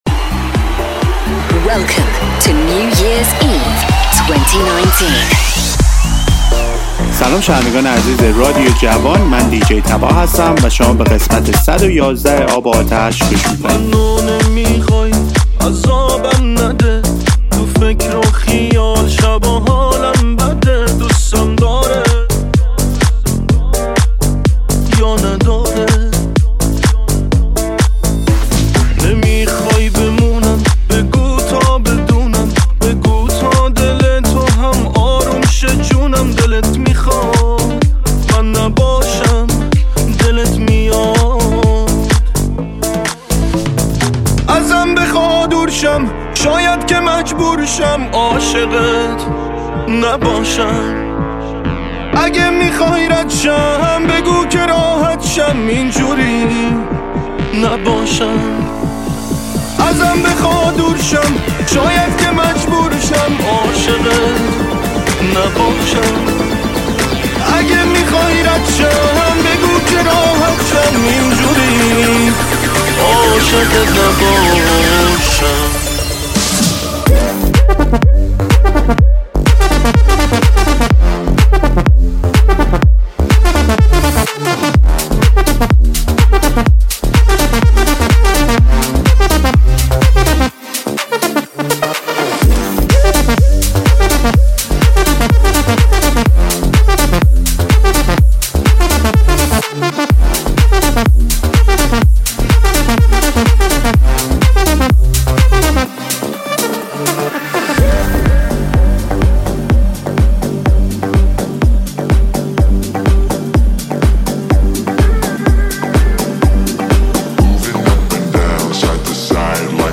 دانلود ریمیکس شاد 2019 مخصوص کریسمس
دانلود ریمیکس شاد 2019 مخصوص رقص
ریمیکس شاد 2019 مخصوص کریسمس